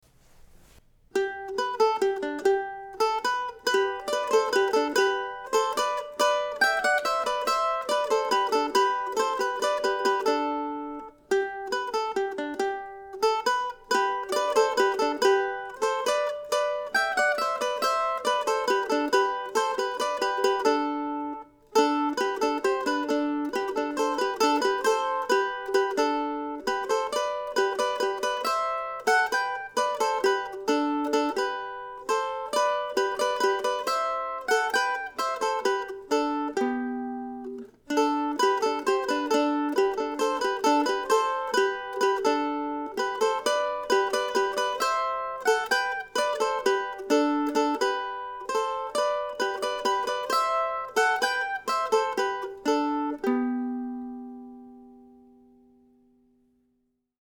Here also is no. 5 from the book of William Bates duettinos.